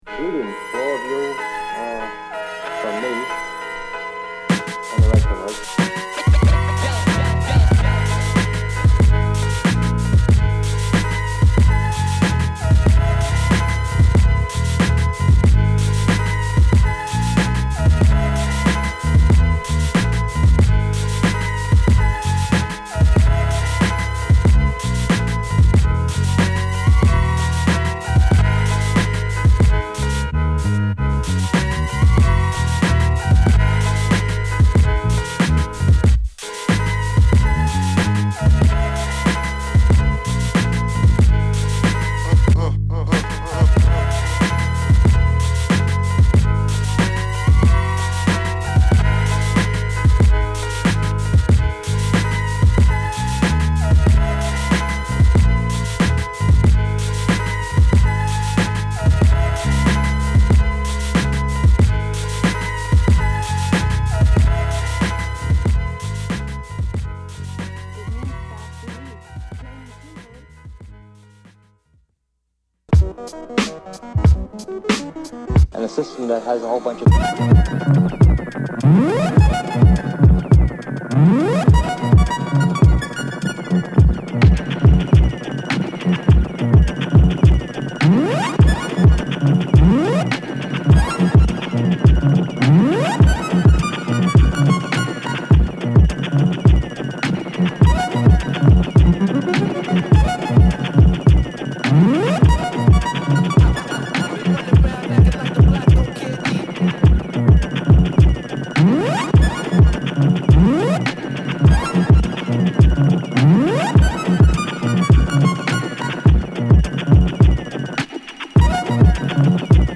アナログ・シンセをfeat.したナイスなインストビートを収録！